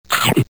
SFX吃东西咀嚼音效下载
SFX音效